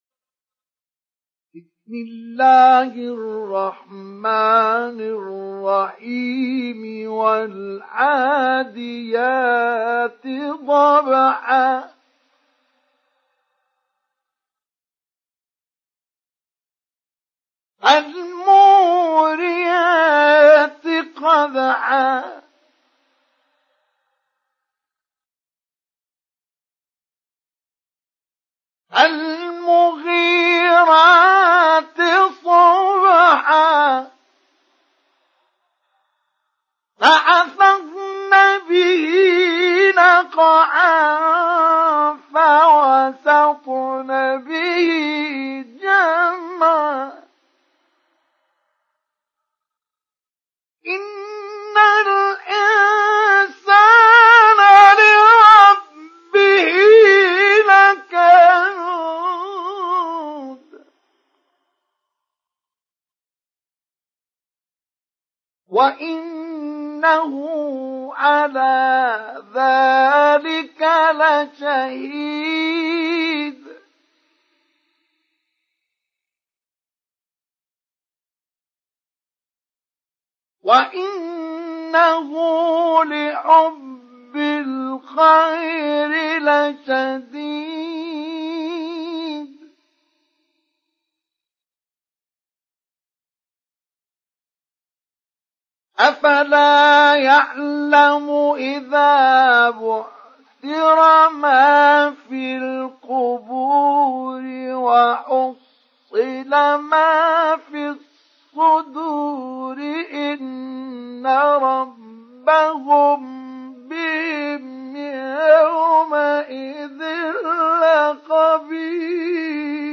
تحميل سورة العاديات mp3 بصوت مصطفى إسماعيل مجود برواية حفص عن عاصم, تحميل استماع القرآن الكريم على الجوال mp3 كاملا بروابط مباشرة وسريعة
تحميل سورة العاديات مصطفى إسماعيل مجود